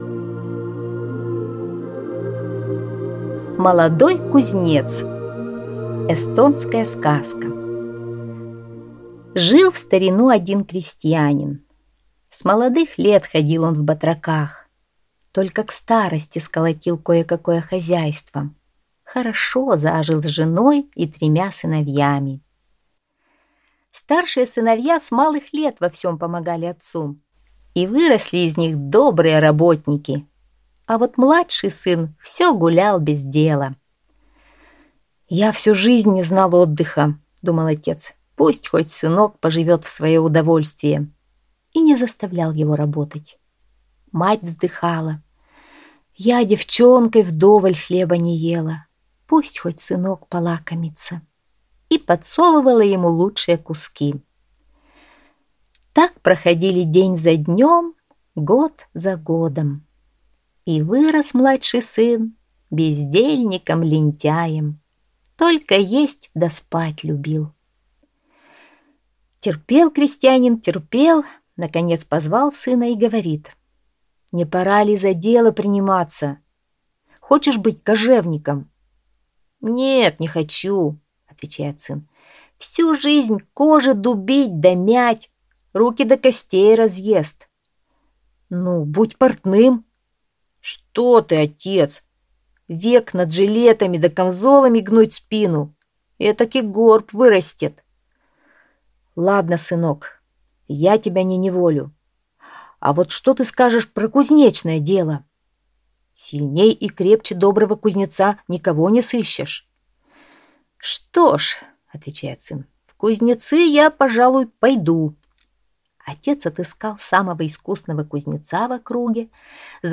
Молодой кузнец - эстонская аудиосказка. Было у крестьянина три сына. Когда младший сын вырос, отец его отправил учиться на кузнеца...